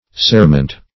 Cerement \Cere"ment\, n. [L. cera wax: cf. F. cirement.]